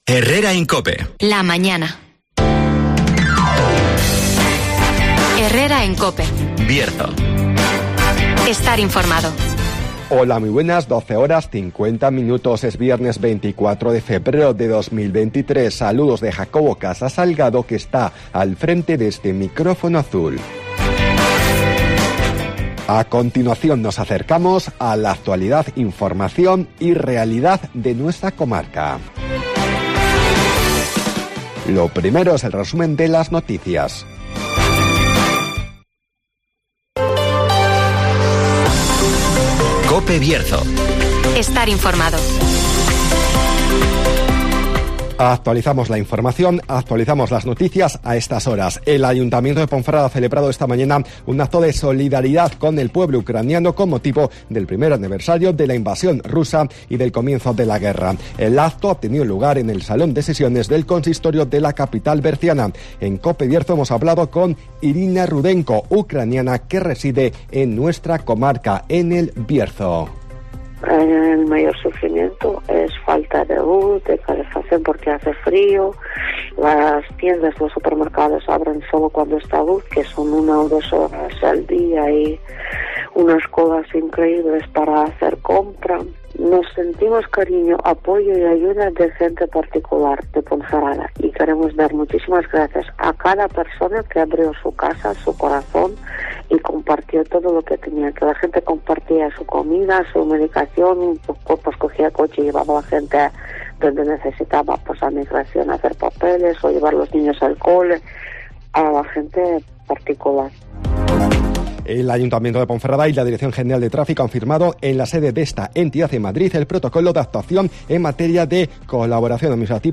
AUDIO: Resumen de las noticias, el tiempo y la agenda